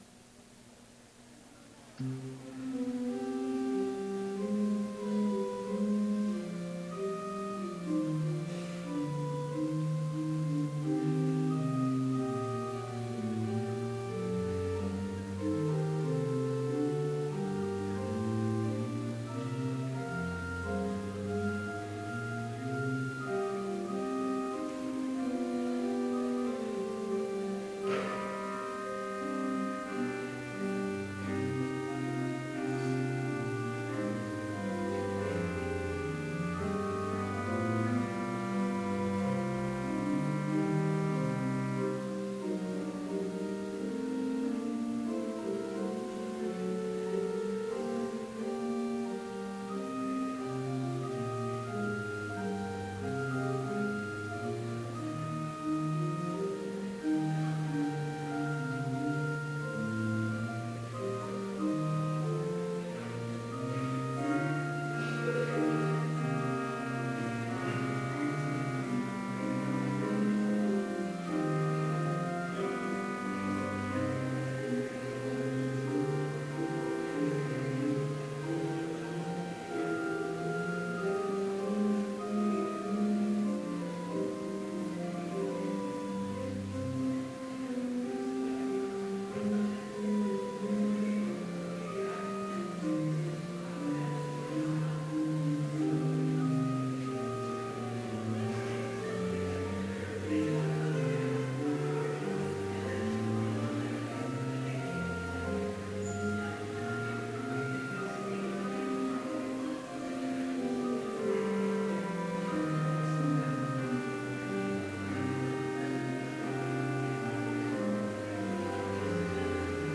Complete service audio for Chapel - February 22, 2016